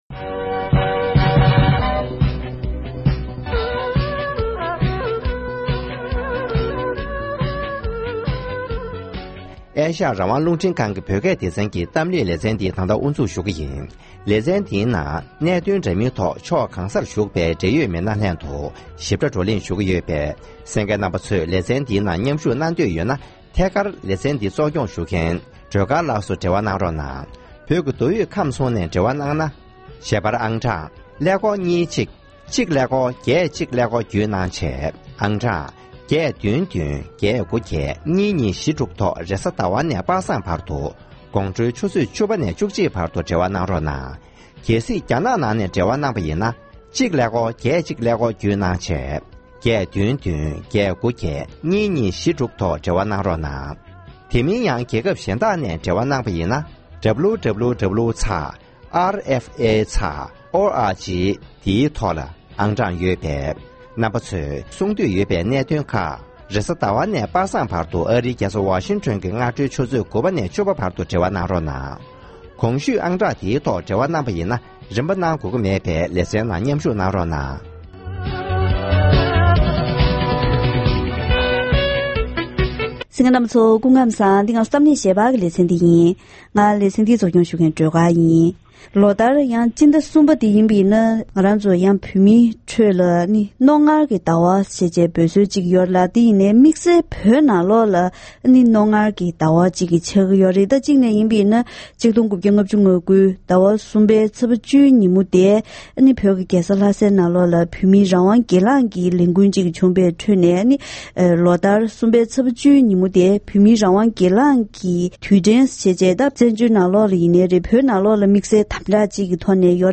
༄༅། །དེ་རིང་གི་གཏམ་གླེང་ཞལ་པར་ལེ་ཚན་ནང་ལོ་རབས་བརྒྱད་བཅུའི་ནང་བོད་ཀྱི་རྒྱལ་ས་ལྷ་སར་ཐོན་པའི་རང་དབང་འཐབ་རྩོད་ཀྱི་ལས་འགུལ་ཆེ་བ་གསུམ་གྱི་ཐོག་ལས་འགུལ་ནང་དངོས་སུ་མཉམ་ཞུགས་གནང་མཁན་དང་ལྷན་དུ་ཕྱིར་དྲན་ཞུས་པ་ཞིག་གསན་རོགས་གནང་།